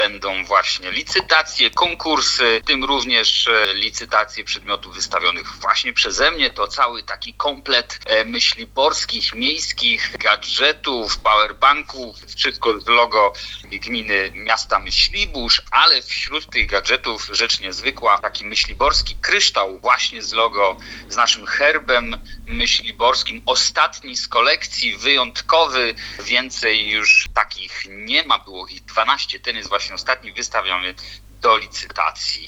– Mówi Piotr Sobolewski, burmistrz Myśliborza.